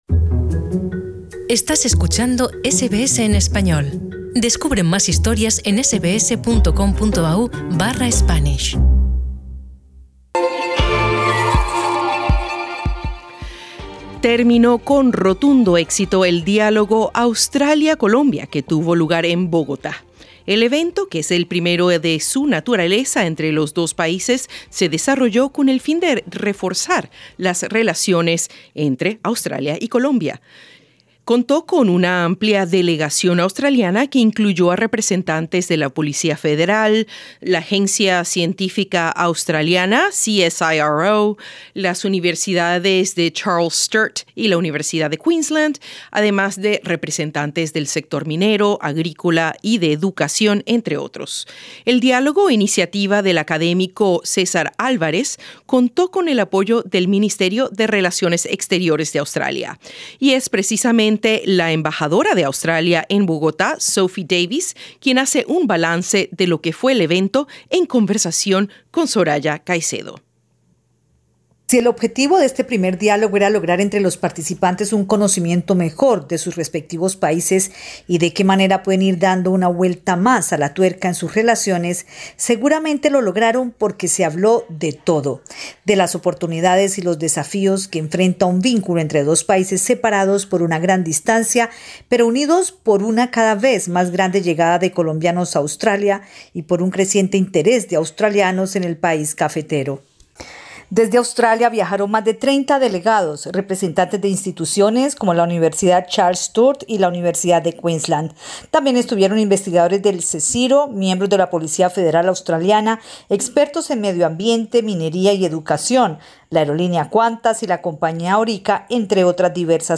Al terminar con éxito el diálogo Australia-Colombia, el primero que hacen estos dos países para fortalecer la relación bilateral, la embajadora australiana en Bogotá, Sophie Davis, destaca en entrevista con SBS Spanish que los estudiantes internacionales son la punta de lanza de una relación que se fortalece cada día más.